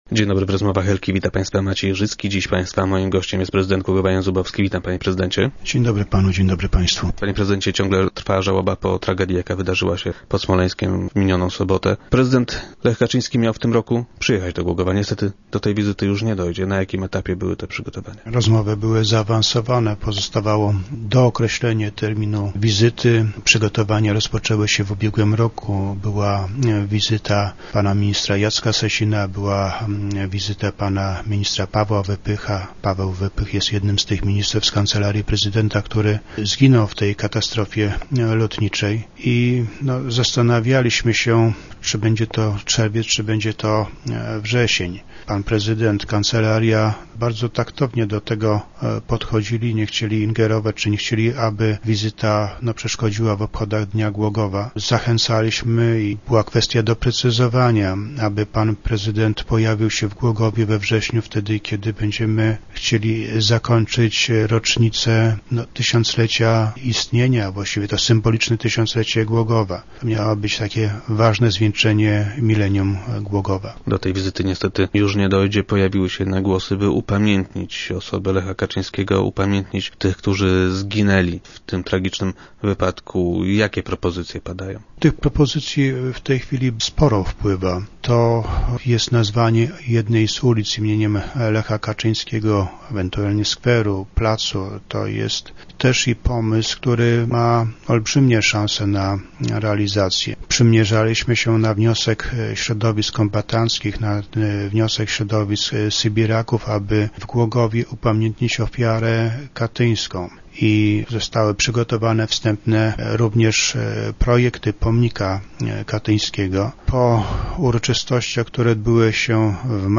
Na zakończenie tych obchodów miał przyjechać prezydent Lech Kaczyński. - Przygotowania do tej wizyty trwały od dawna - mówi prezydent Jan Zubowski, który był dziś gościem Rozmów Elki.